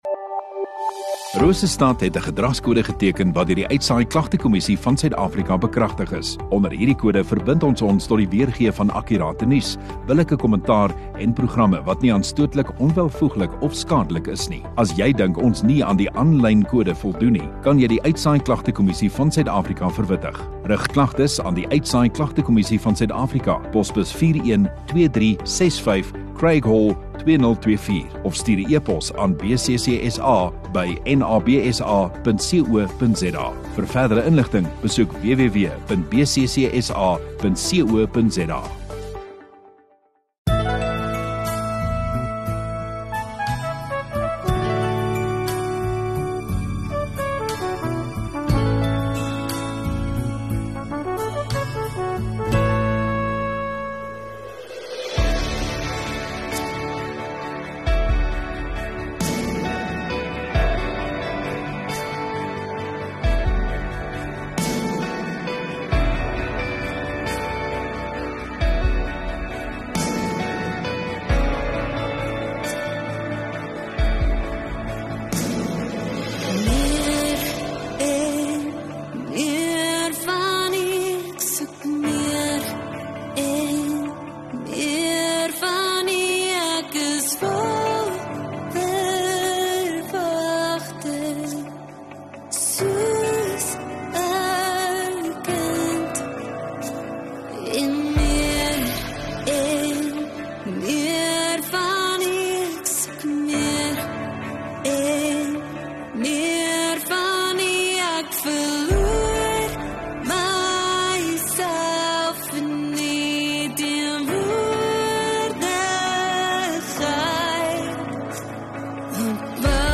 View Promo Continue Install Rosestad Godsdiens 3 Nov Sondagaand Erediens